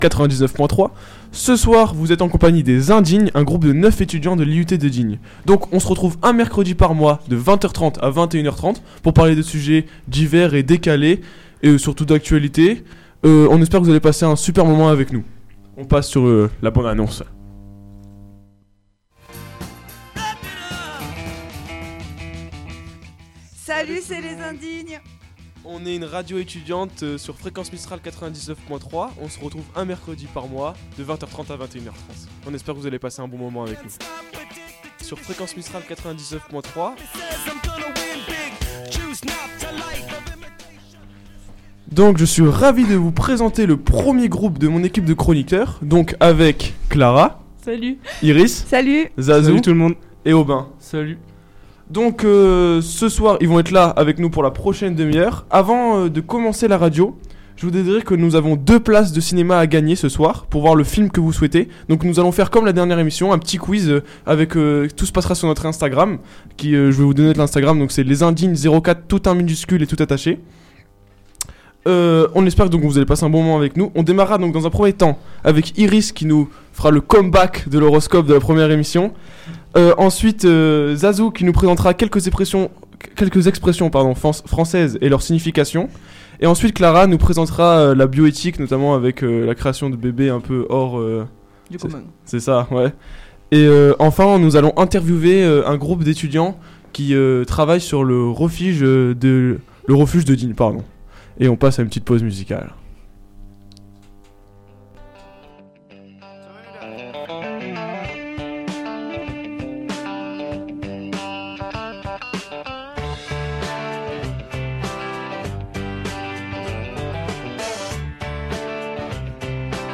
Ce soir, ils sont 9 pour cette seconde aventure radio + 3 invités.
Interview de 3 étudiants à propos du chenil de Digne les bains Les divers sujets abordés ce soir ......